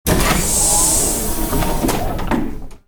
hissingdooropen.ogg